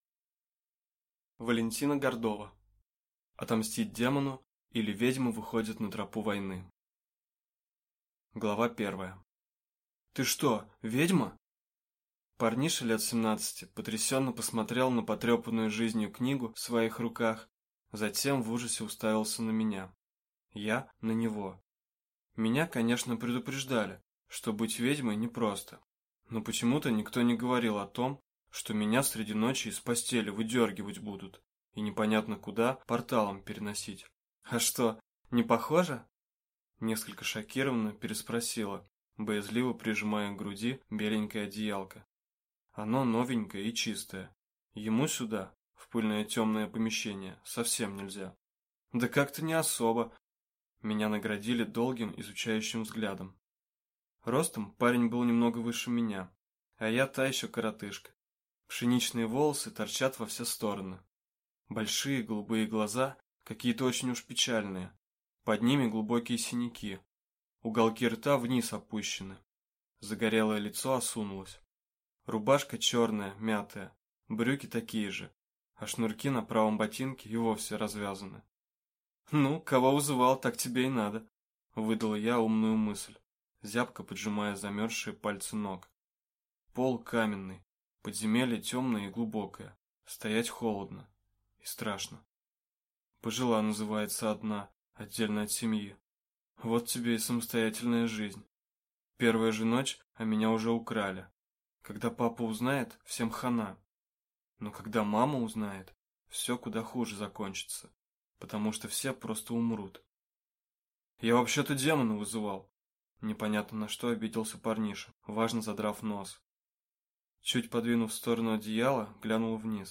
Аудиокнига Отомстить демону, или Ведьма выходит на тропу войны | Библиотека аудиокниг